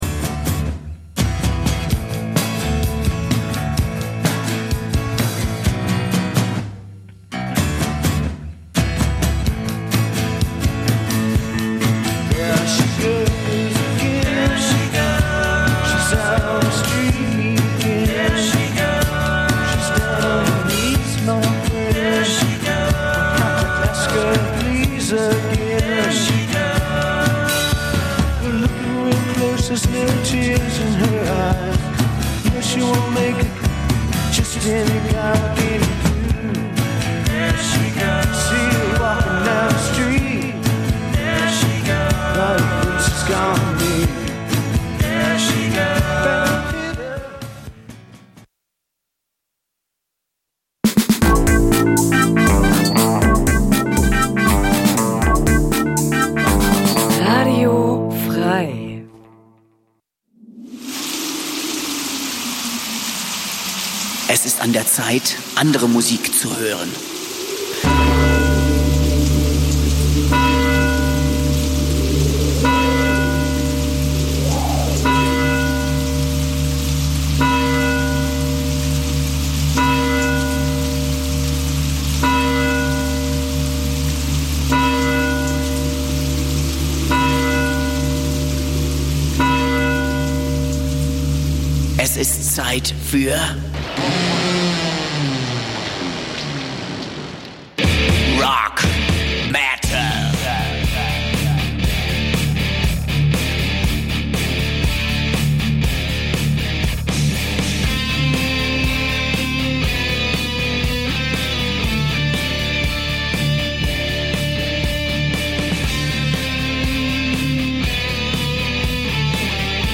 Musik von Rock bis Metal!